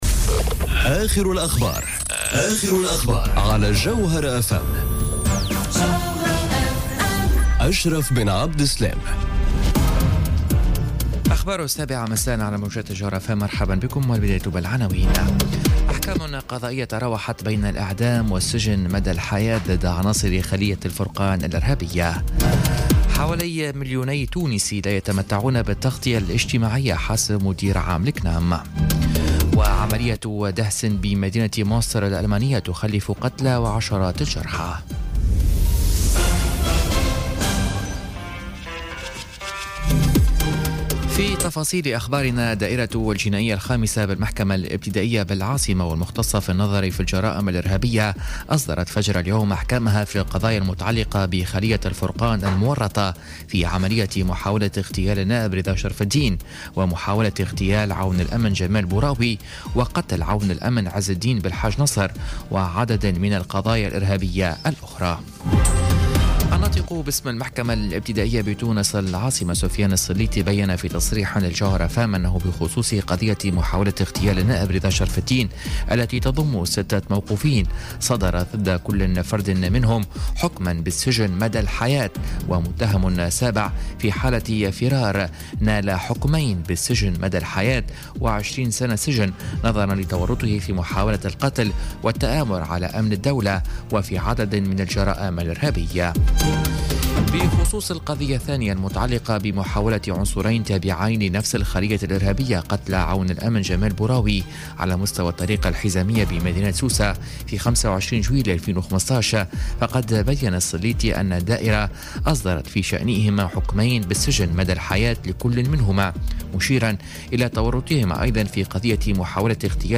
نشرة أخبار السابعة مساء ليوم السبت 7 أفريل 2018